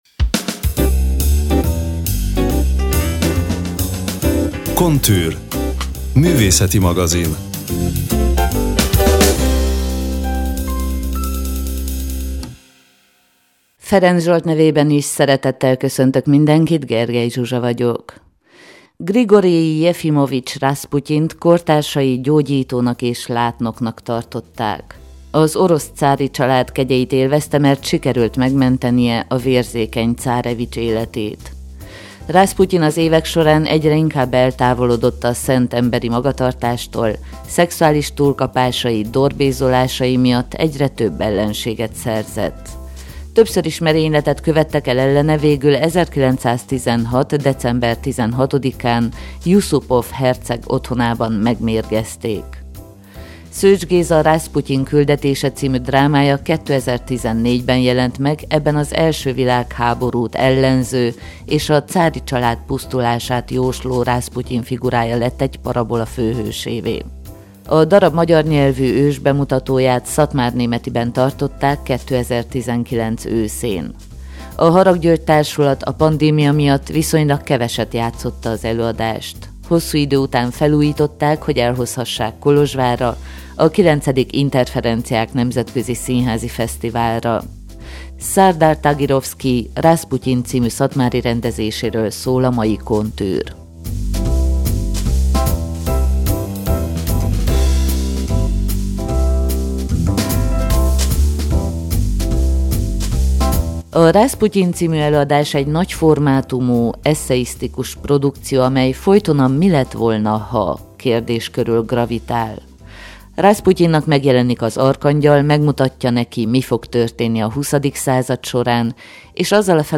Kolozsváron, a 9.Interferenciák Nemzetközi Színházi Fesztiválon